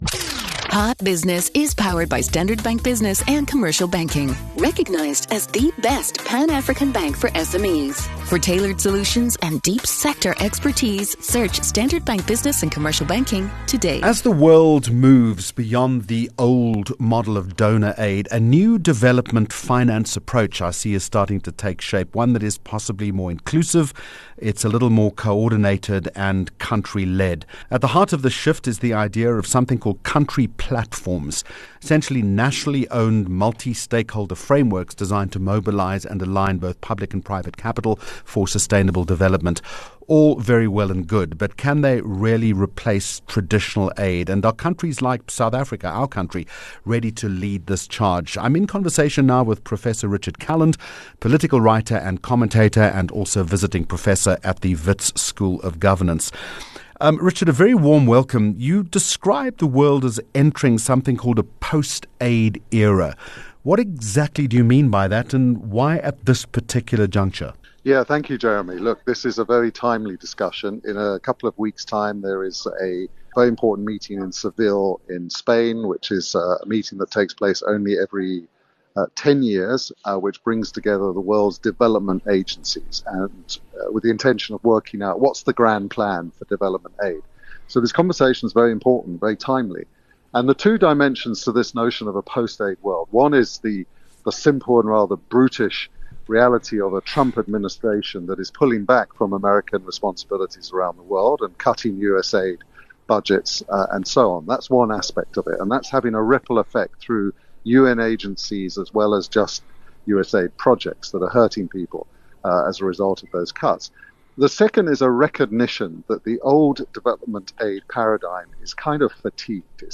19 Jun Hot Business Interview